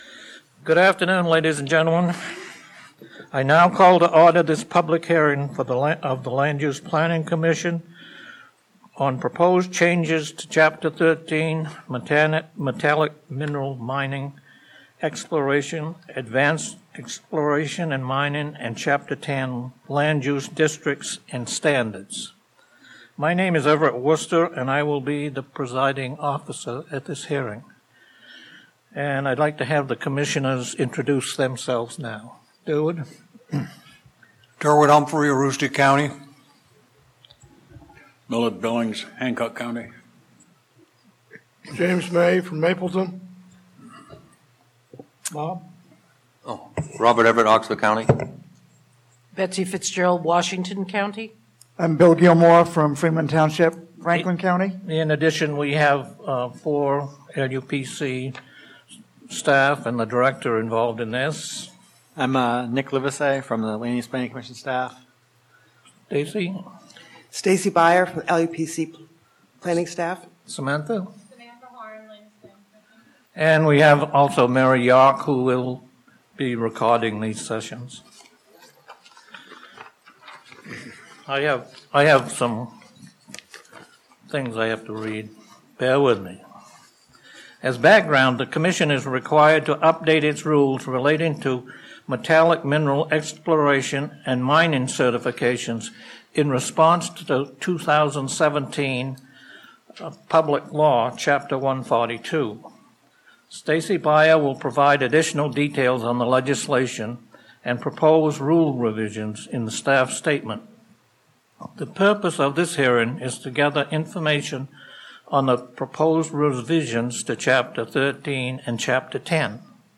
Public Hearing Audio File (MP3)